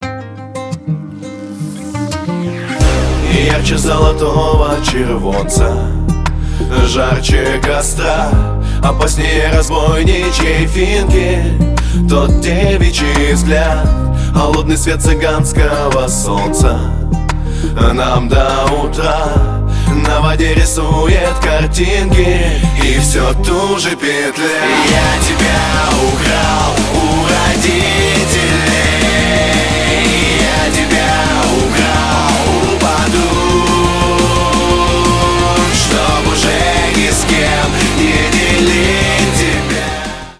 Рок Металл # Рэп и Хип Хоп